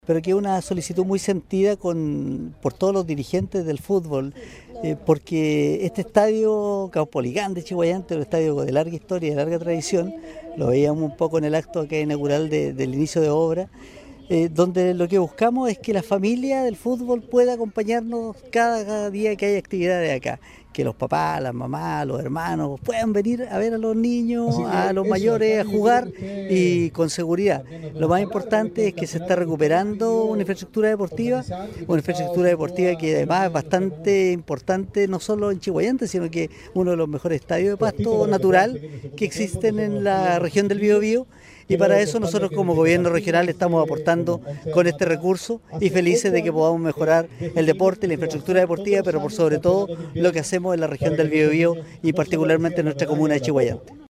En esa línea, el Consejero Regional Andres Parra declaró que “es muy importante que como Gobierno Regional, podamos ir con estos aportes de recursos a una solicitud del municipio”.